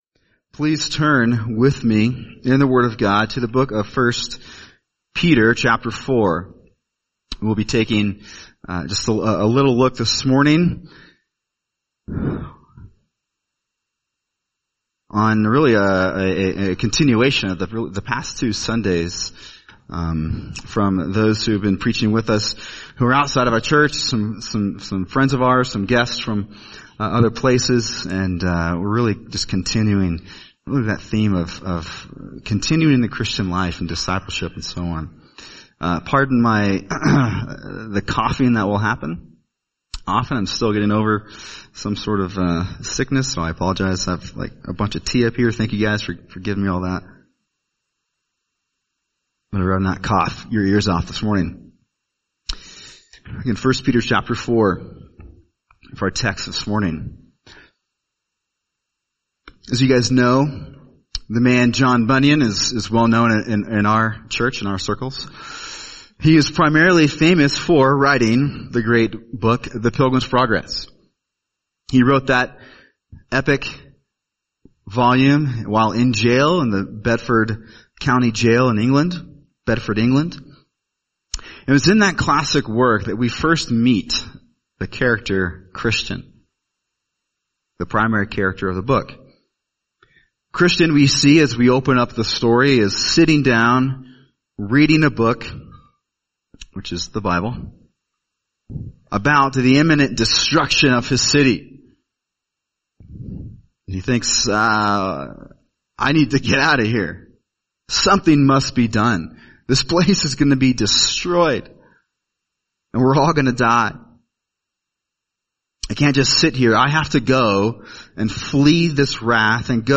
[sermon] 1Peter 4:7-11 Sojourning in a Dying Land | Cornerstone Church - Jackson Hole